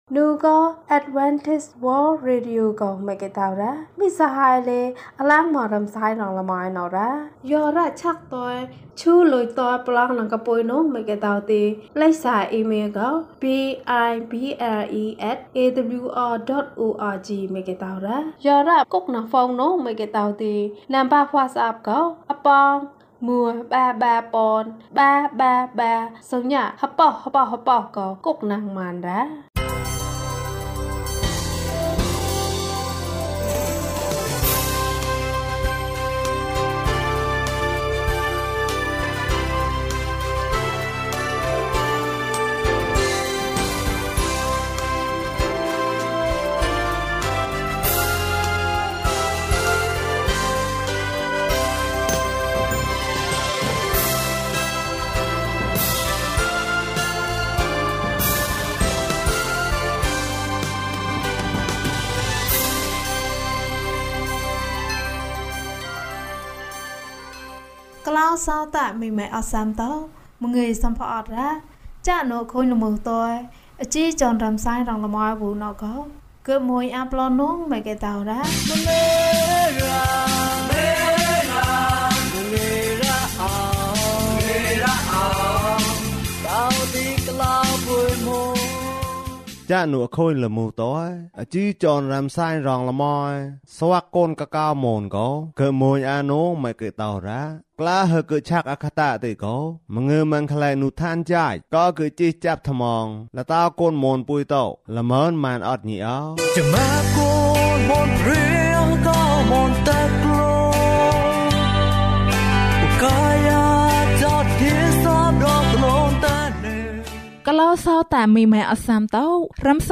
ပင်လယ်ထဲမှာ လမ်းလျှောက်။၀၁ ကျန်းမာခြင်းအကြောင်းအရာ။ ဓမ္မသီချင်း။ တရား‌ဒေသနာ။